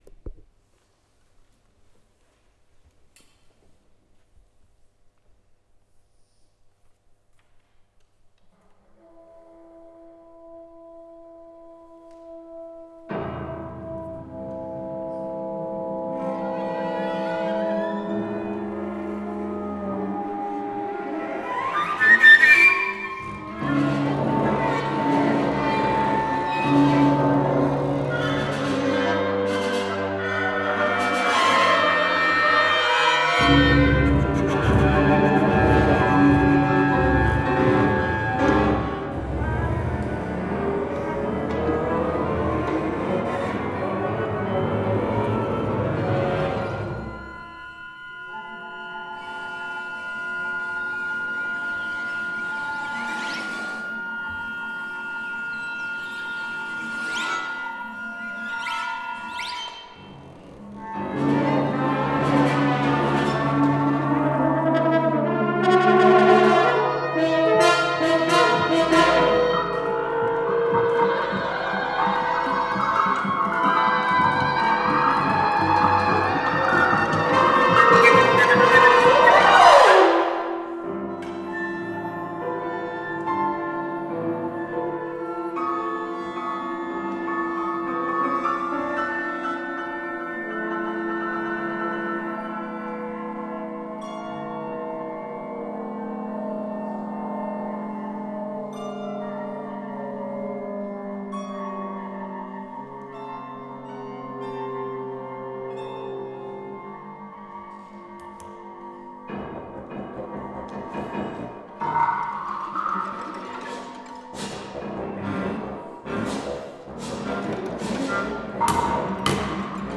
für Kammerorchester Fl,Ob,Kl,Fg,Hn,Tr,Pos,2Prc,Pno,2Vl,Vla,Vc,Kb